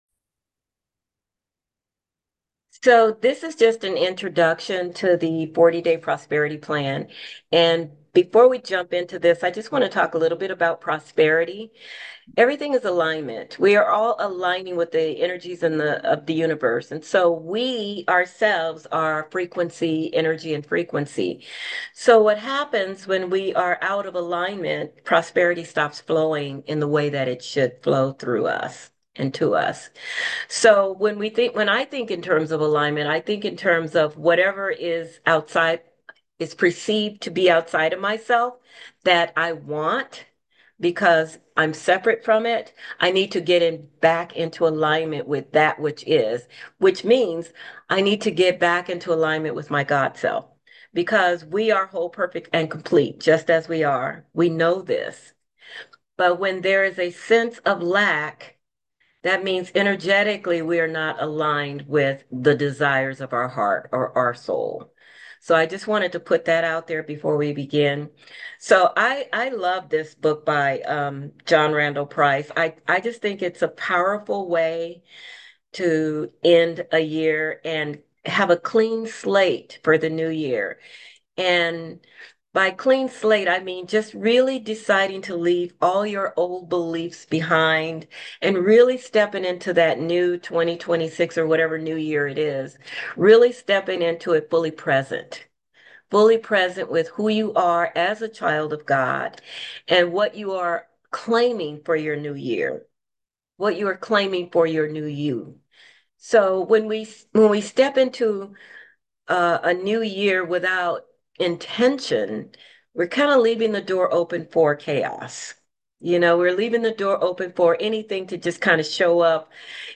Click to Listen to a Live Recording